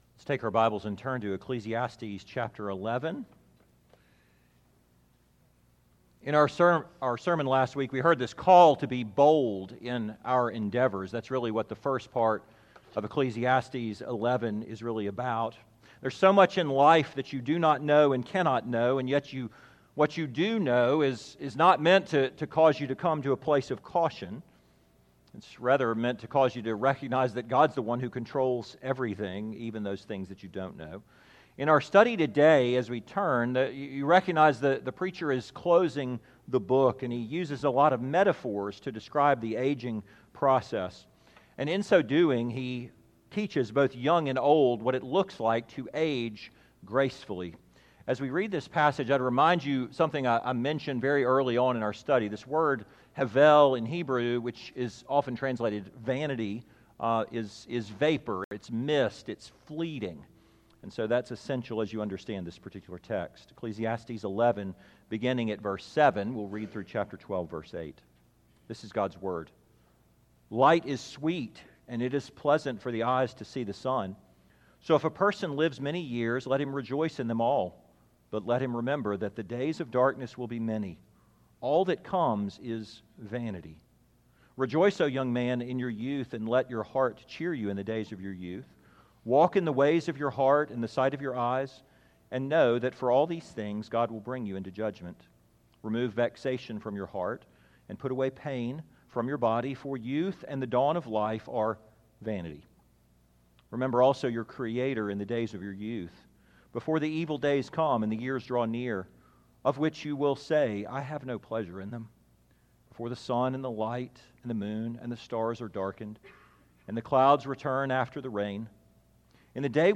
2025 How to Age with Grace Preacher